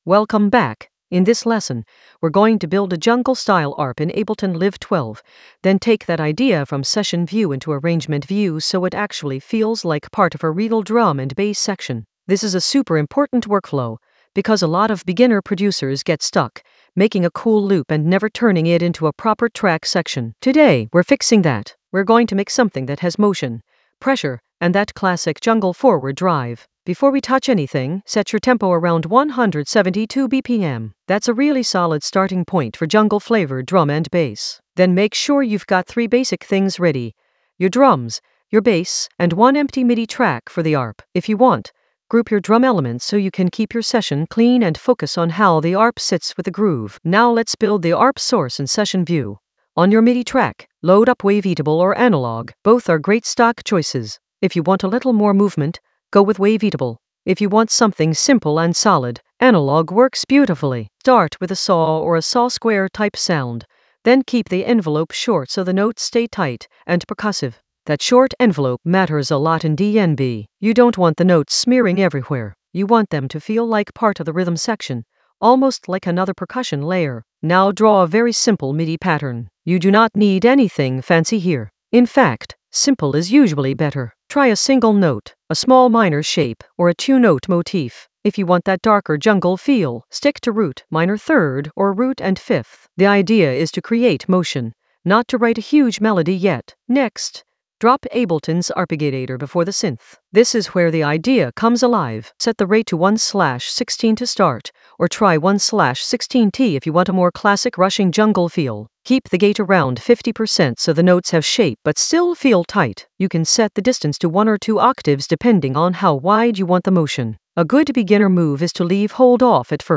An AI-generated beginner Ableton lesson focused on Stack a jungle arp using Session View to Arrangement View in Ableton Live 12 in the Drums area of drum and bass production.
Narrated lesson audio
The voice track includes the tutorial plus extra teacher commentary.